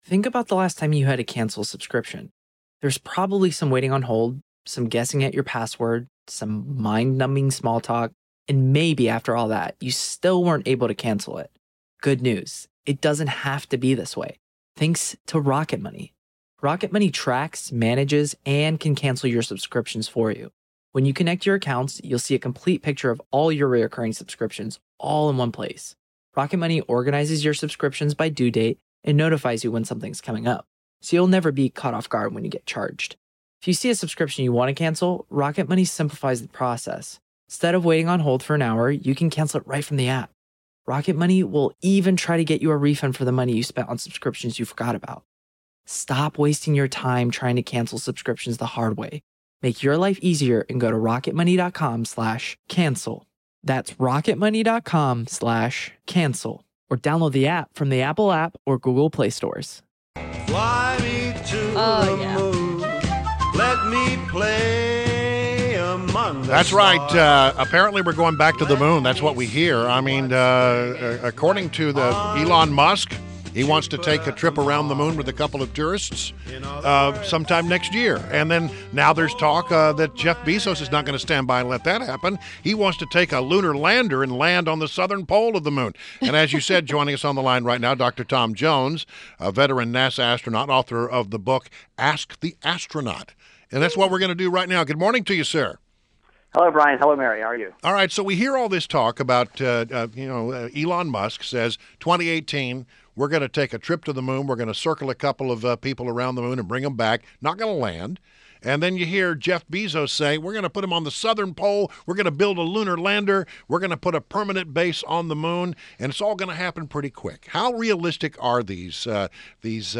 WMAL Interview - Astronaut TOM JONES - 03.06.17
INTERVIEW — DR. TOM JONES — veteran NASA astronaut and author of new book “Ask the Astronaut.”